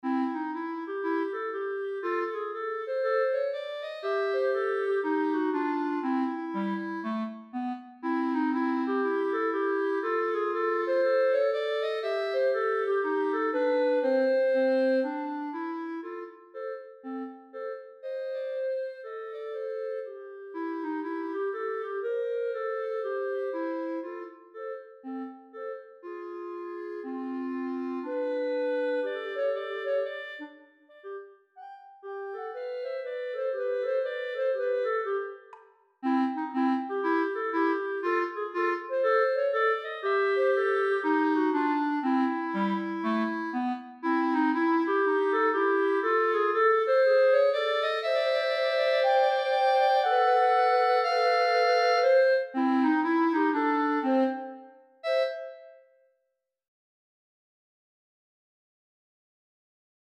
clarinet duet for intermediate players